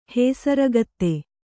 ♪ hēsaragatte